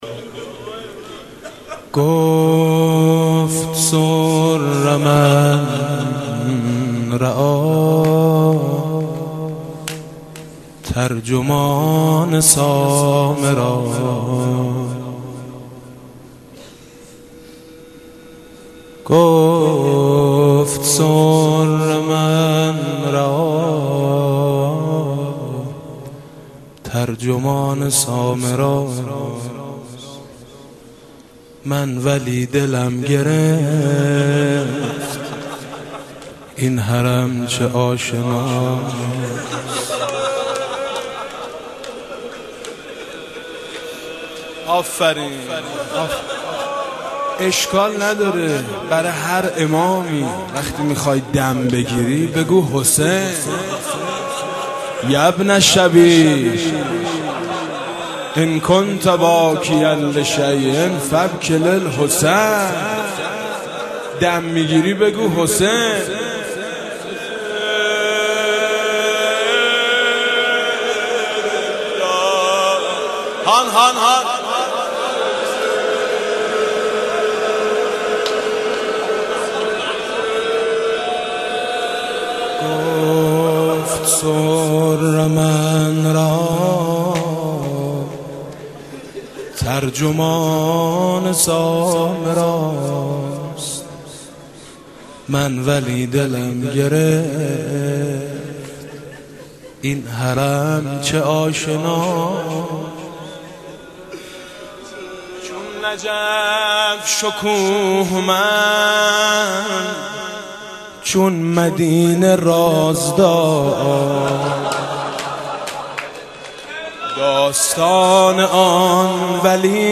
مداحی حاج میثم مطیعی بمناسبت شهادت امام هادی (ع)
دانلود حاج میثم مطیعی شهادت امام هادی ع روضه وارث اخبار مرتبط انقلاب درونی مناجات خوان معروف تهران نماز عید فطر چگونه است؟